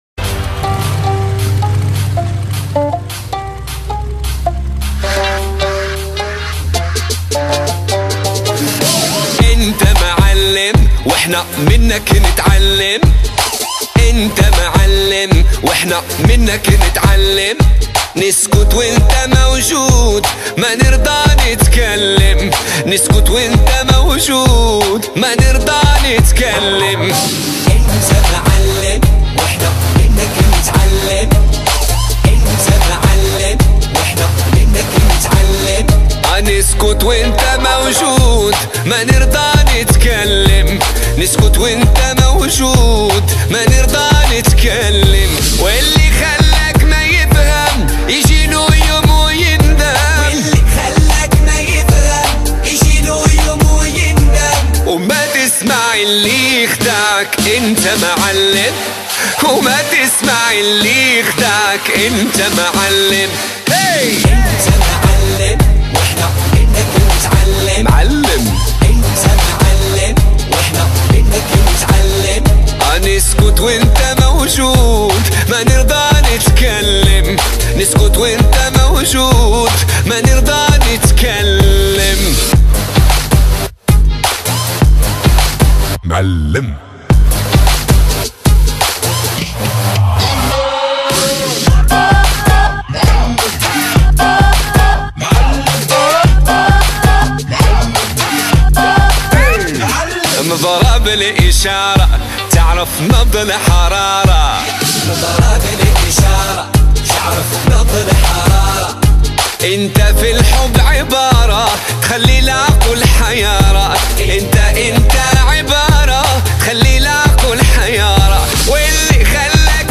آهنگ شاد عربی